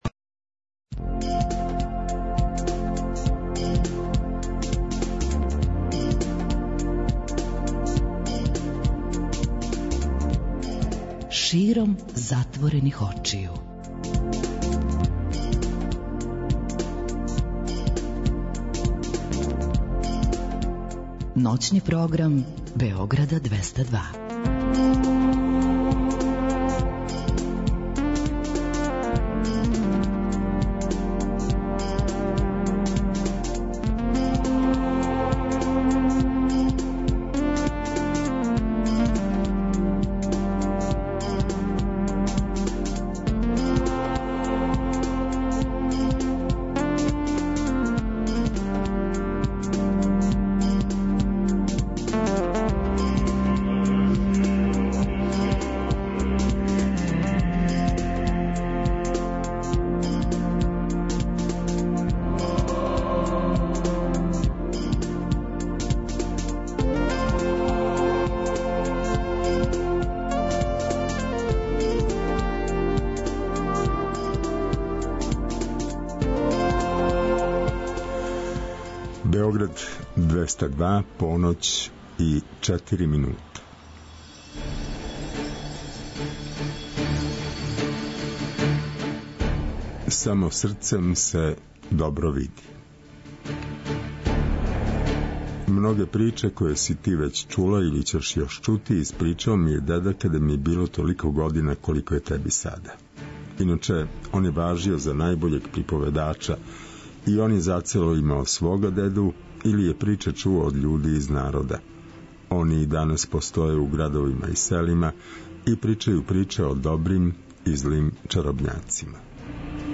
Тако некако изгледа и колажни контакт програм "САМО СРЦЕМ СЕ ДОБРО ВИДИ".